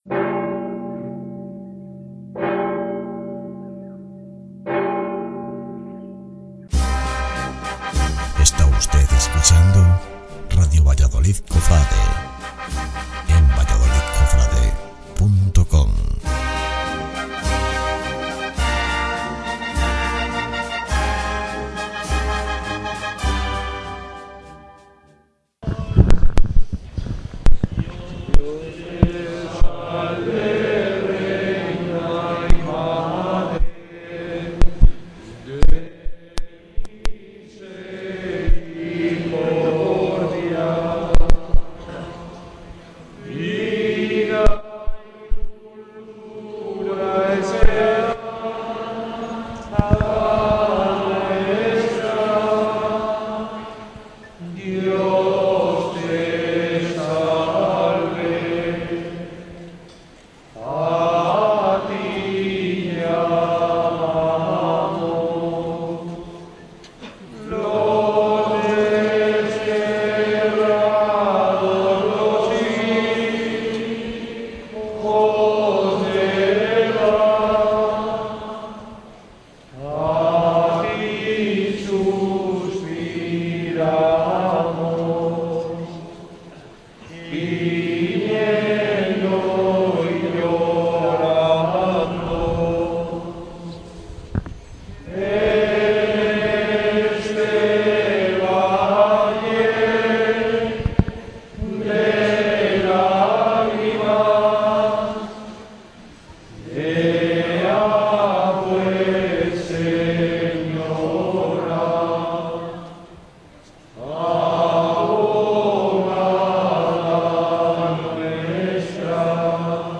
Canto de la Salve Popular a Ntra.